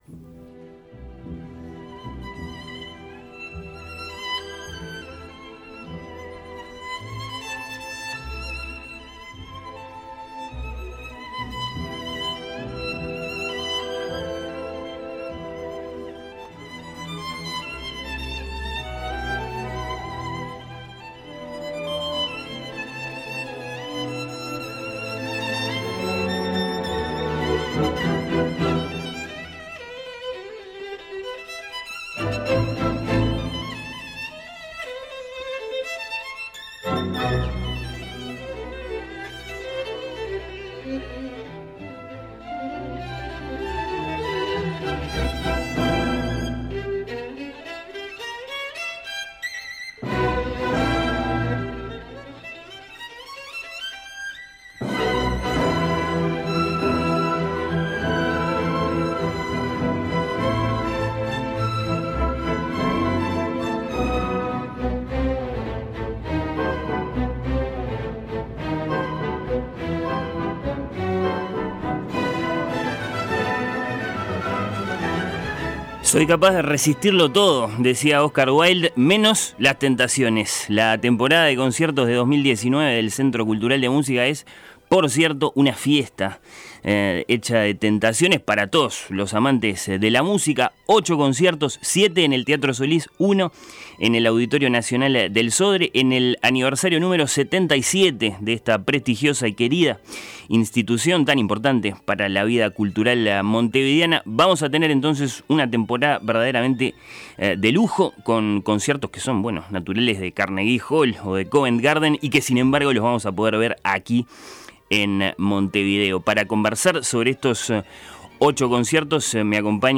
Se escuchó un fragmento del Concierto para violín en mi menor, Op. 64 de Felix Mendelssohn, por Janine Jansen junto a la BBC Symphony Orchestra.